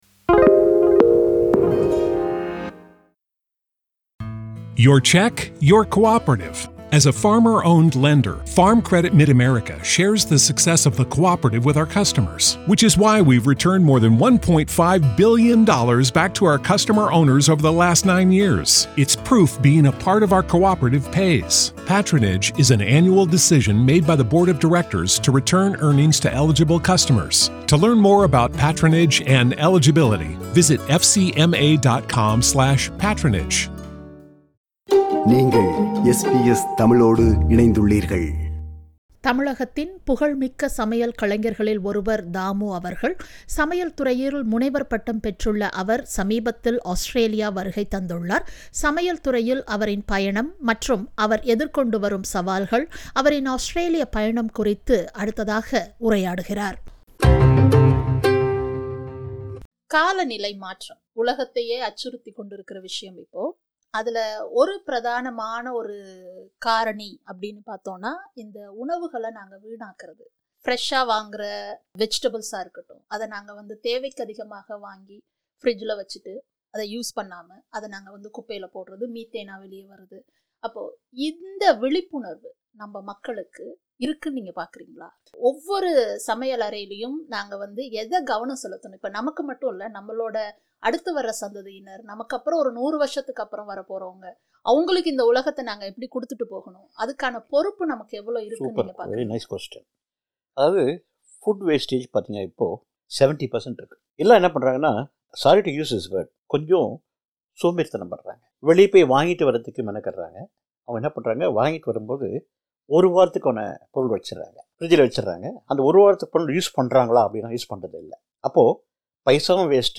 சமையல்கலைஞர் தாமு அவர்களின் நேர்காணலின் நிறைவு பாகம்.